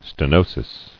[ste·no·sis]